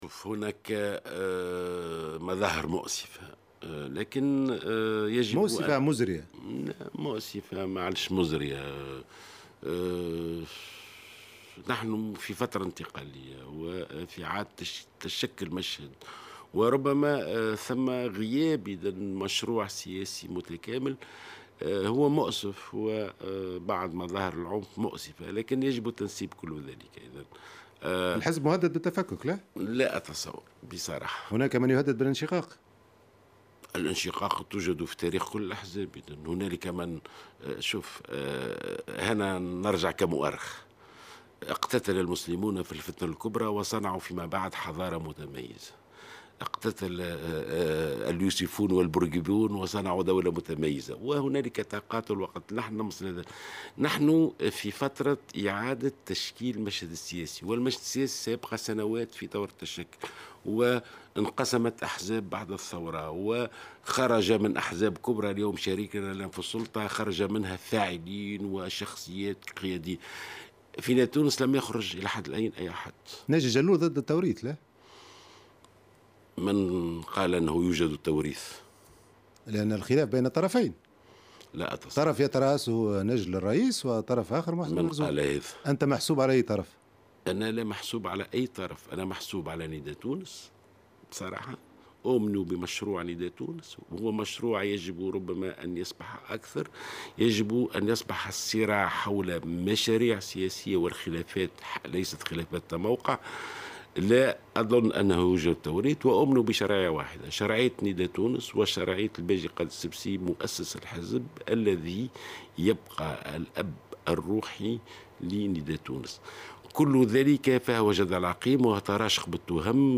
Dans une interview accordée à France 24, le ministre de l’éducation Neji Jalloul a nié appartenir à l’une des deux « parties » qui partagent actuellement Nidaa Tounes.